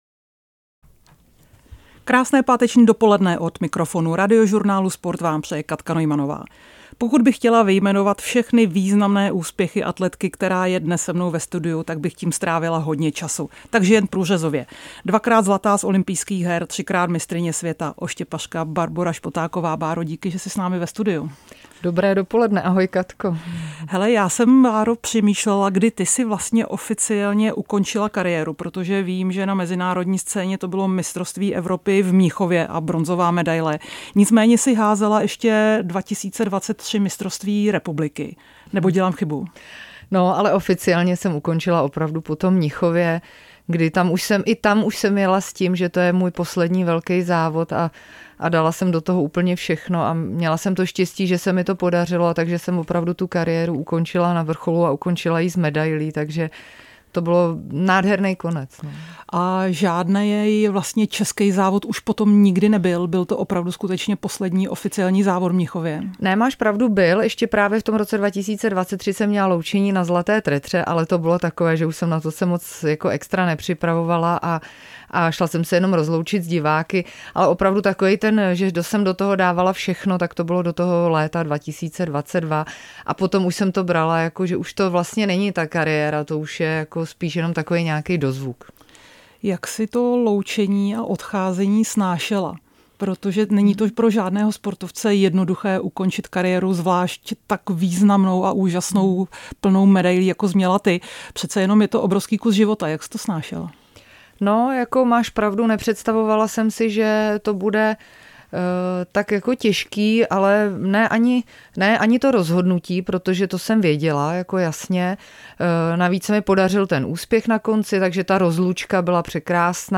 Páteční finiš Kateřiny Neumannové: Svého hosta zpovídá bývalá běžkyně na lyžích, olympijská vítězka ze ZOH v Turíně 2006, šestinásobná olympijská medailistka, dvojnásobná mistryně světa - 13.06.2025